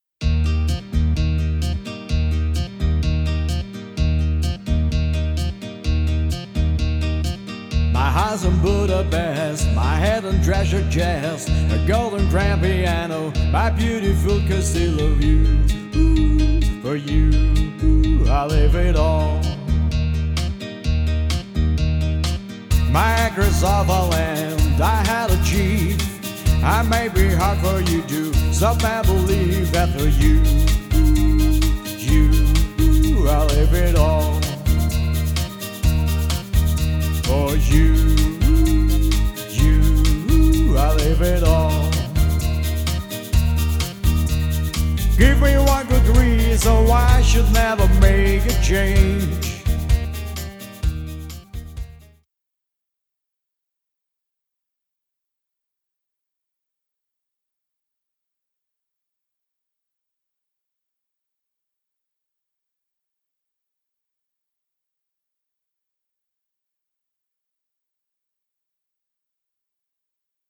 Pop & Rock & Swing